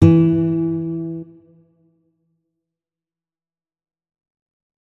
guitar